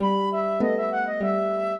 flute-harp
minuet9-9.wav